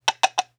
SFX_pasosCaballo4.wav